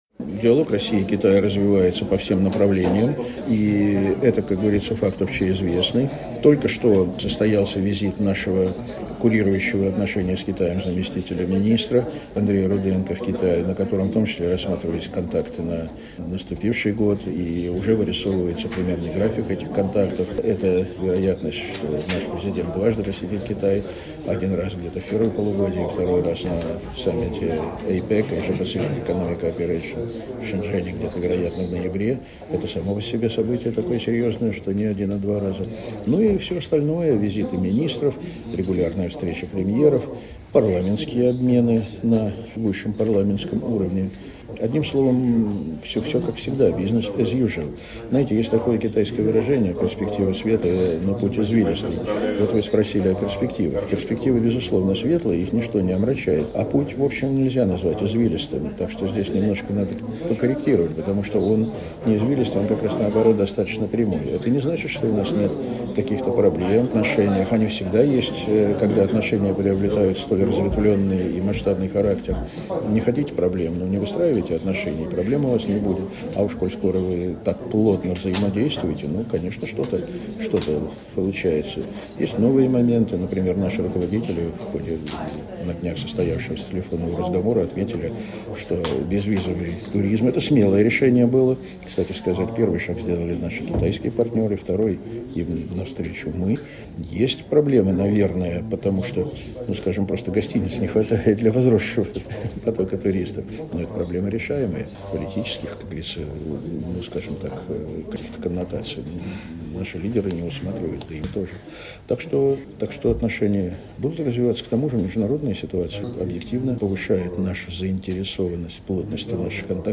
ГЛАВНАЯ > Актуальное интервью
Первый заместитель председателя Комитета Совета Федерации по международным делам Андрей Денисов в интервью журналу «Международная жизнь» рассказал о российско-китайских отношениях: